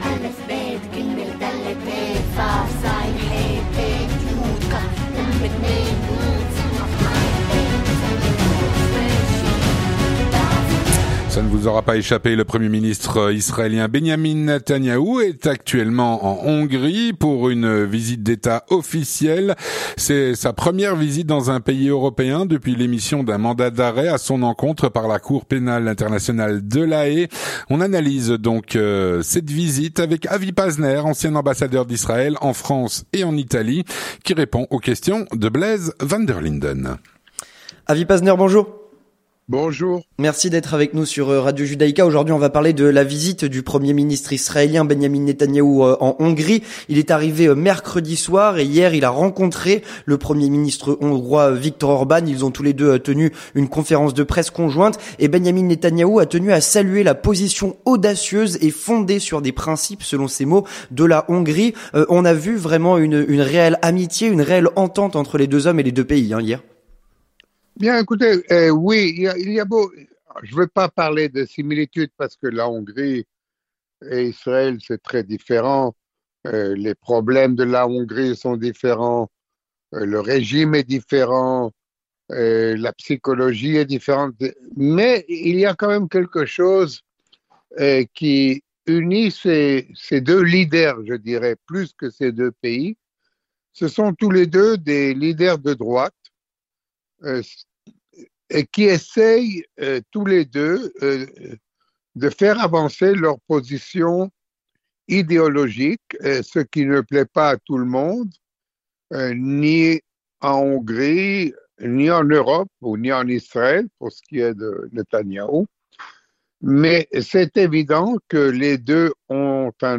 Analyse de cette visite avec Avi Pazner, ancien Ambassadeur d’Israël en France et en Italie.